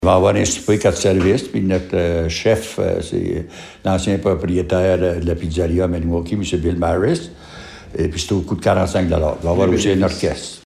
Le maire de Messines, Ronald Cross, donne quelques détails :